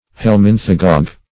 Search Result for " helminthagogue" : The Collaborative International Dictionary of English v.0.48: Helminthagogue \Hel*min"tha*gogue\, n. [Gr.